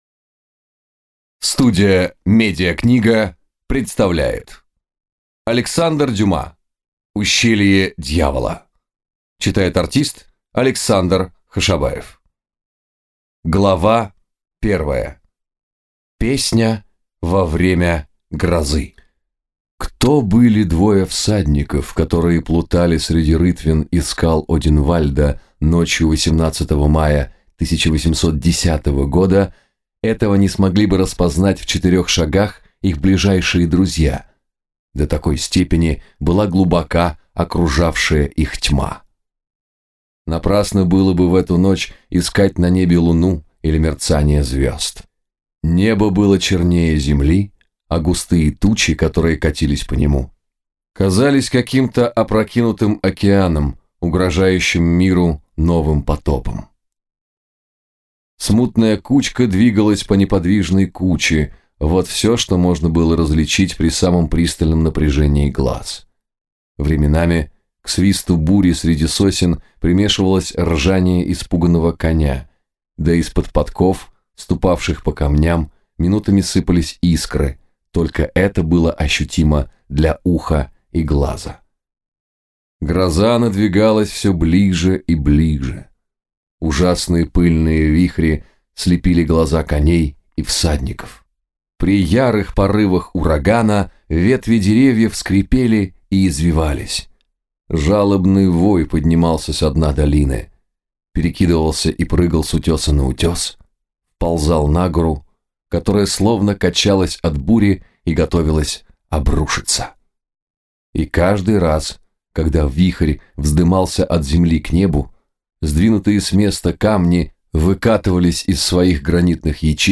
Аудиокнига Ущелье дьявола | Библиотека аудиокниг